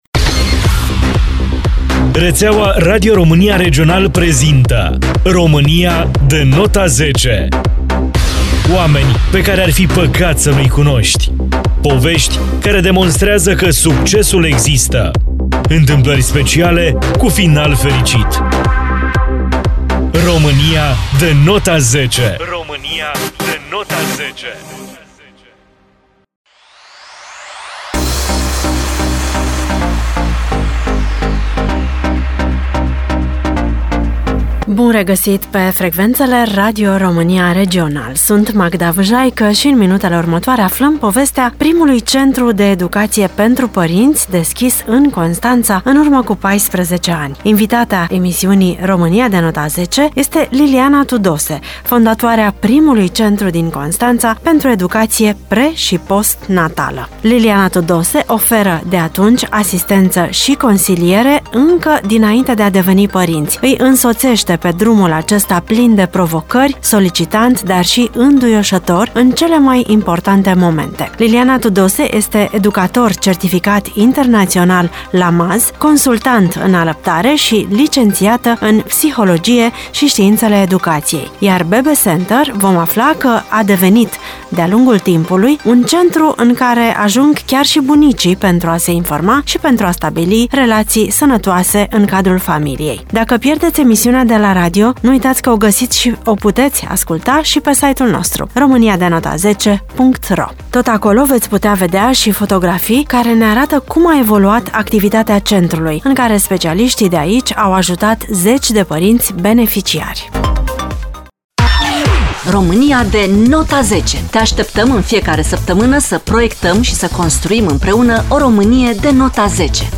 Invitata ediției